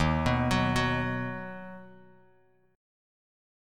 Ebm#5 chord